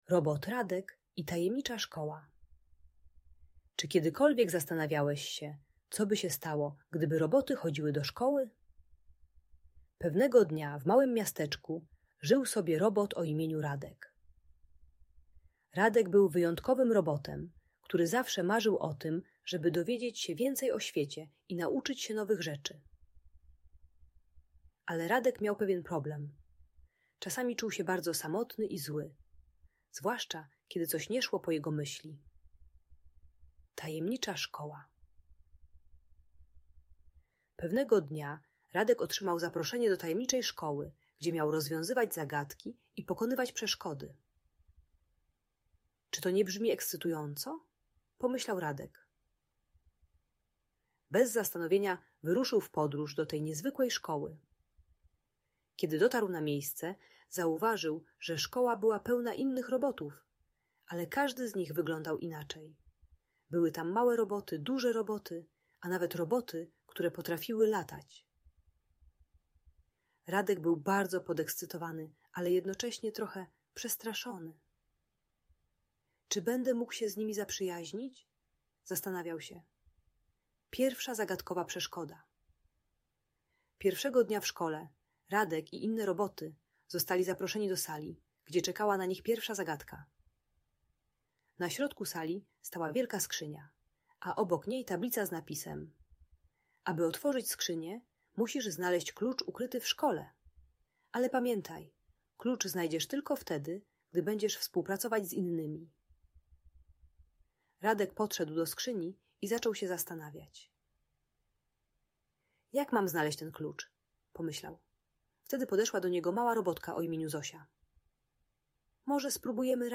Robot Radek i Tajemnicza Szkoła - Audiobajka dla dzieci